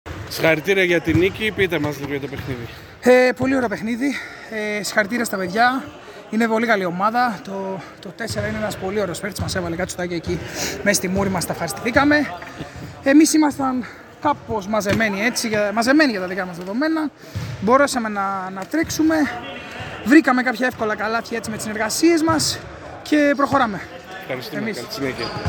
GAME INTERVIEWS: